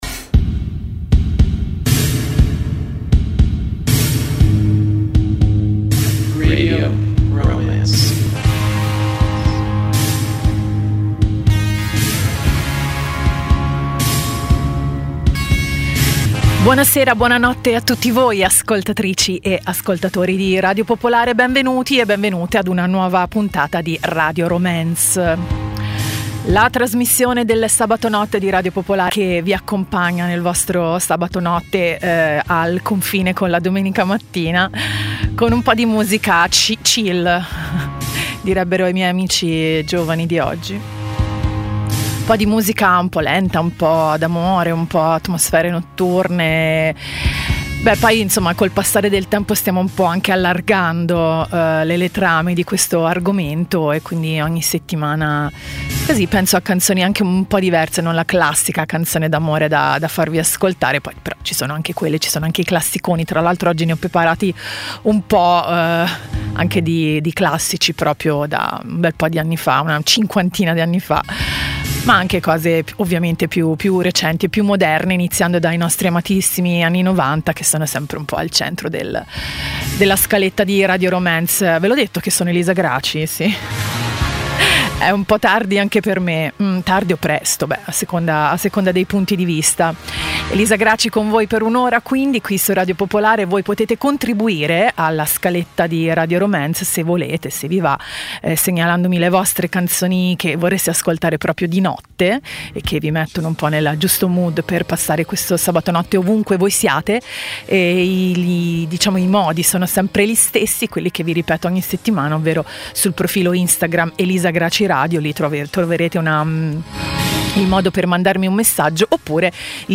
Canzoni d'amore, di desiderio, di malinconia, di emozioni, di batticuore.